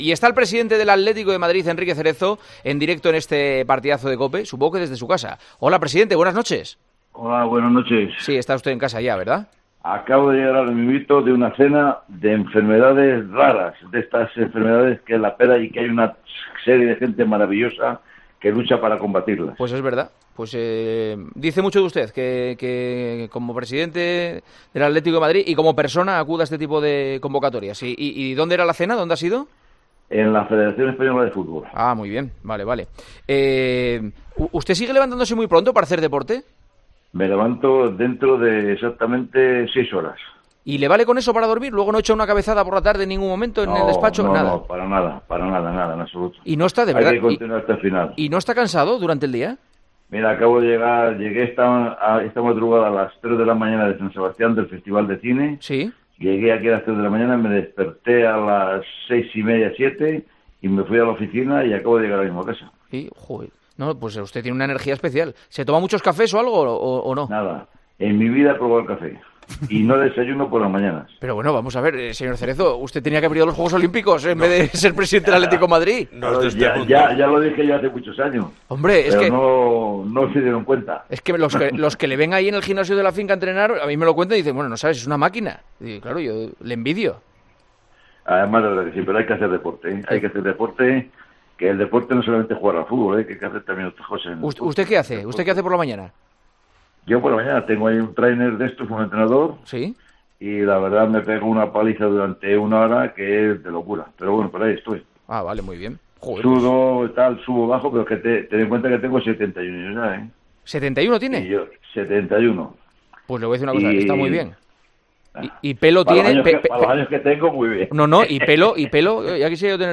Enrique Cerezo atendió la llamada de El Partidazo de COPE este jueves, tras venir de una cena a favor de la lucha contra las enfermedades raras, y a dos días del derbi contra el Real Madrid en el Wanda Metropolitano.